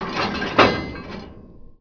leverLarge_01.WAV